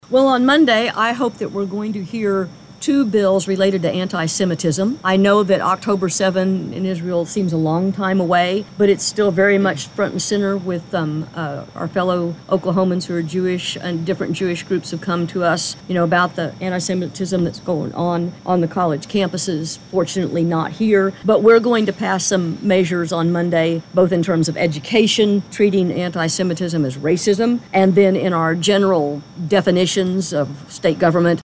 Following the forum, Sen. Daniels, and Reps. Kane and Strom joined KWON in studio for Capitol Call powered by Phillips 66.
Julie Daniels on Next Week 2-28.mp3